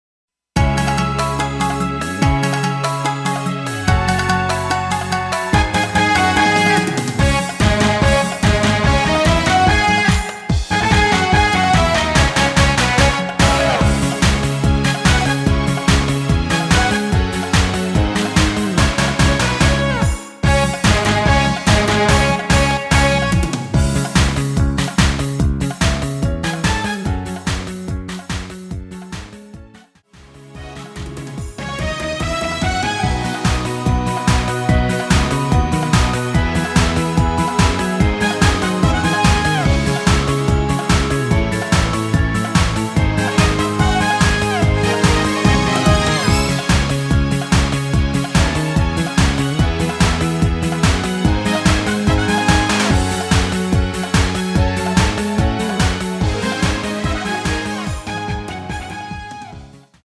C#m
◈ 곡명 옆 (-1)은 반음 내림, (+1)은 반음 올림 입니다.
앞부분30초, 뒷부분30초씩 편집해서 올려 드리고 있습니다.